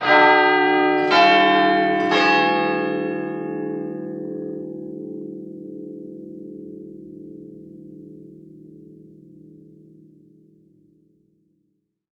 Echoic Heavy Bell